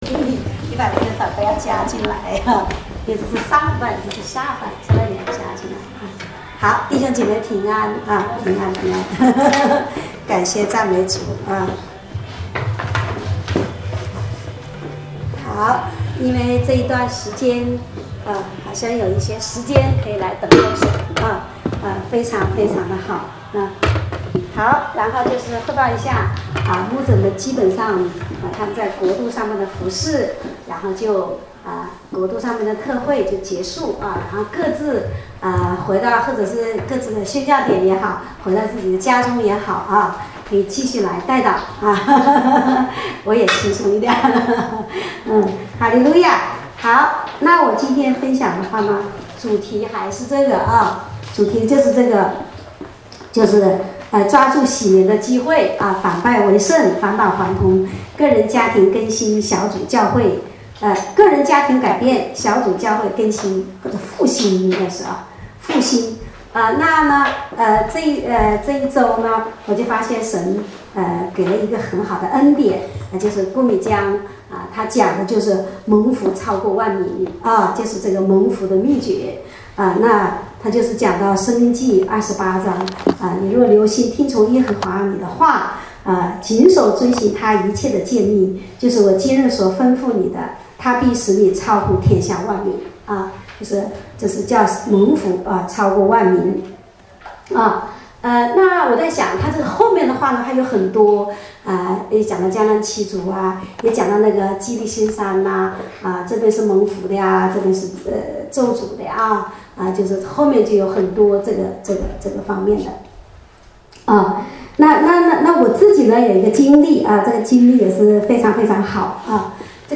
主日恩膏聚会